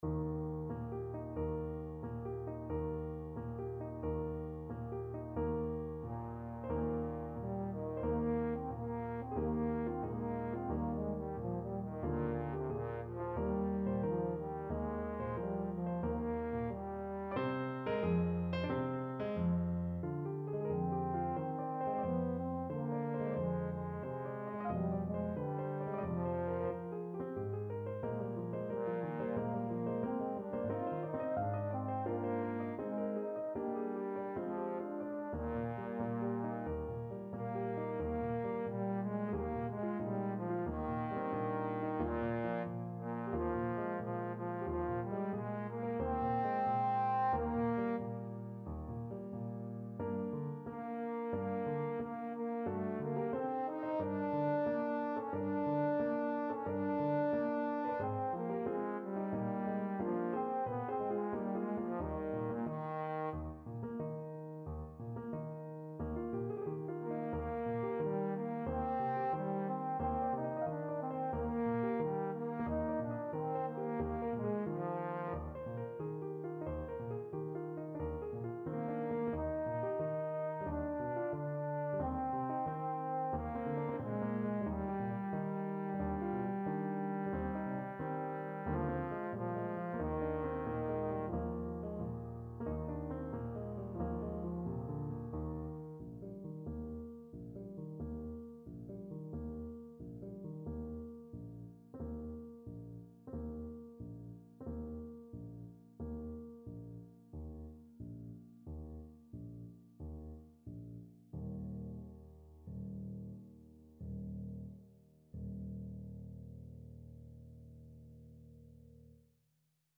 Trombone version
4/4 (View more 4/4 Music)
Moderato =90
Classical (View more Classical Trombone Music)